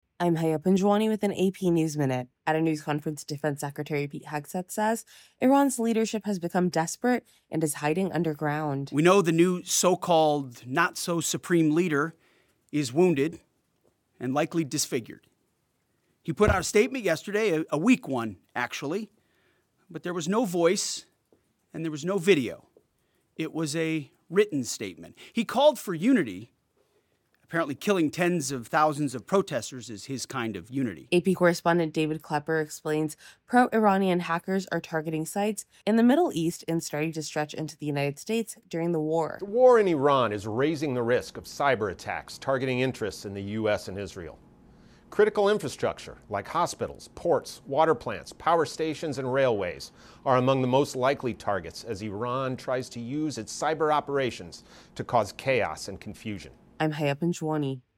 At a news conference, Defense Secretary Pete Hegseth says Iran's leadership has become desperate and is hiding underground.